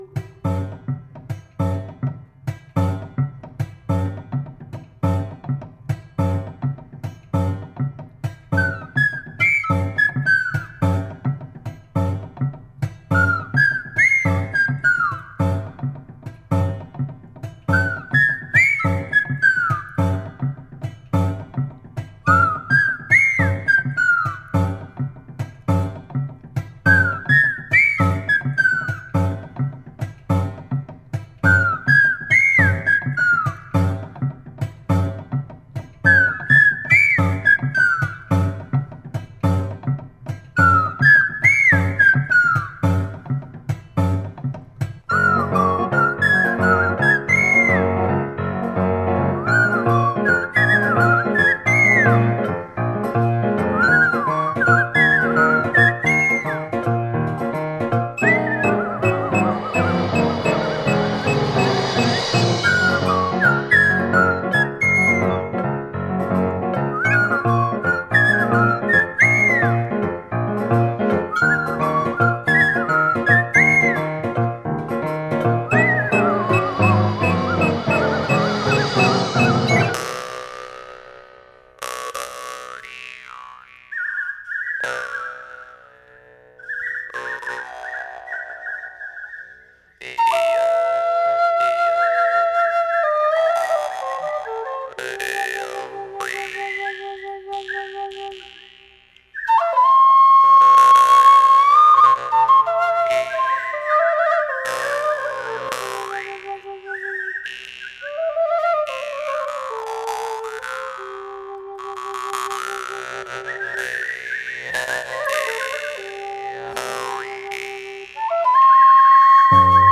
無声映画のノスタルジックでモダンな感覚に、アルゼンチン的な寓話感を加えた傑作！
ジャケットには“映画音楽トリオ”と表記されていますが、正にですね！